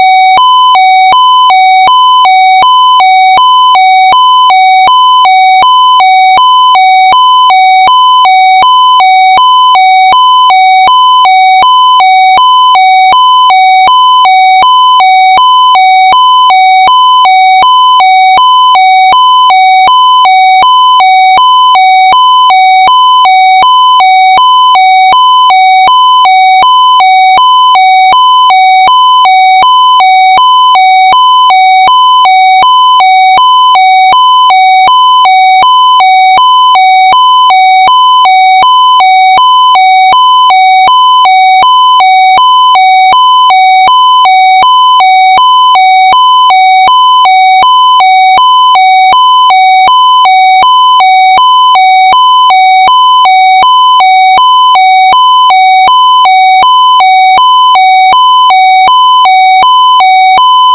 Dukane Hi-Lo 2: